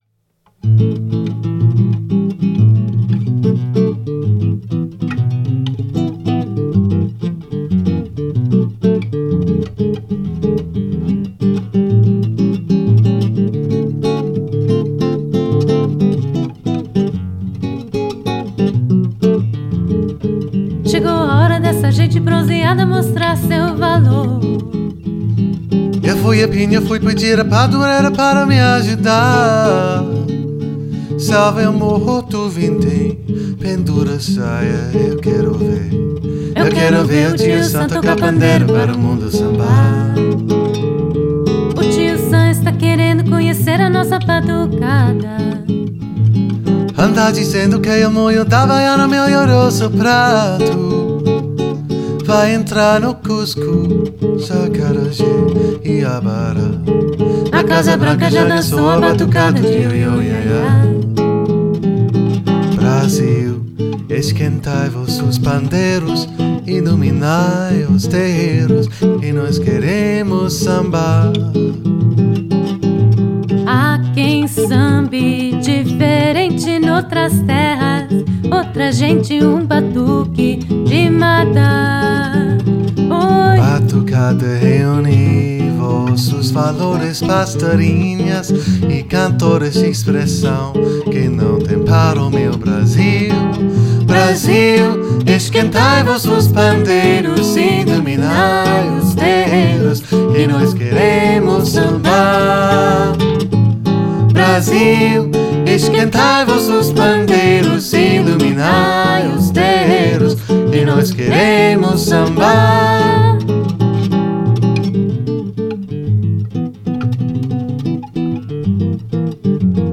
bossa nova duo